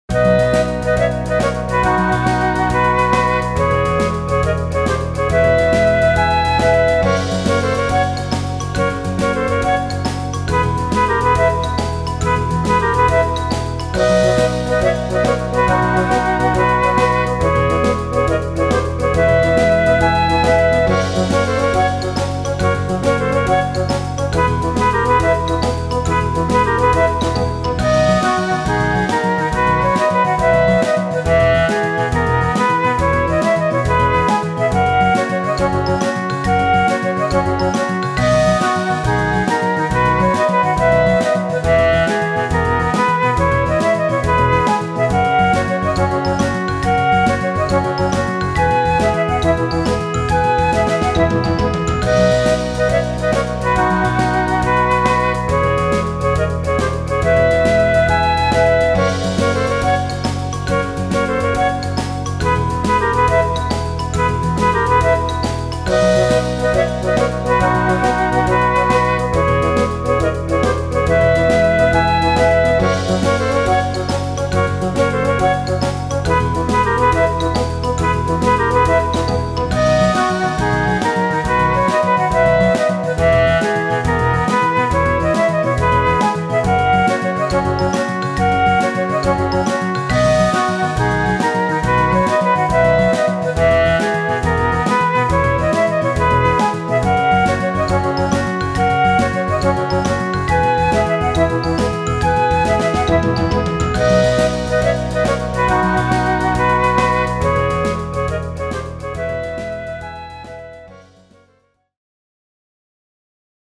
イメージ：さわやか 軽快   カテゴリ：RPG−外・ダンジョン